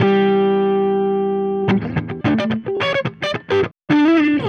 Retro Funkish Guitar 02a.wav